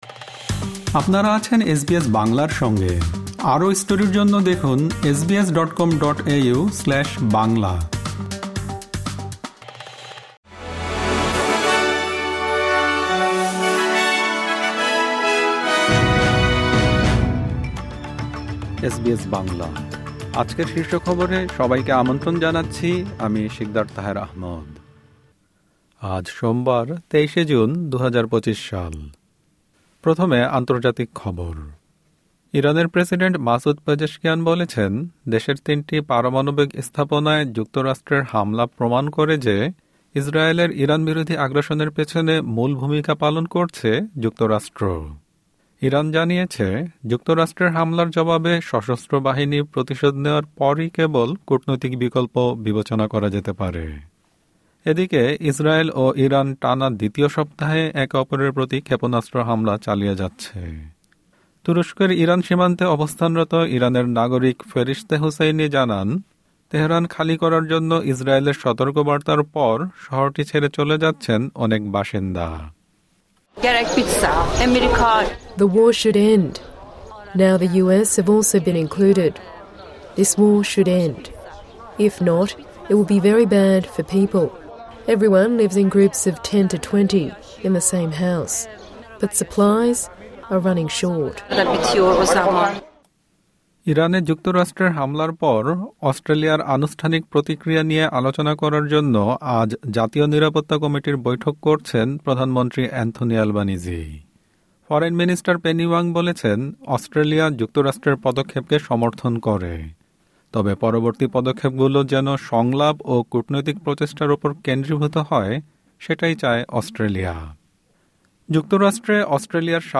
এসবিএস বাংলা শীর্ষ খবর: ২৩ জুন, ২০২৫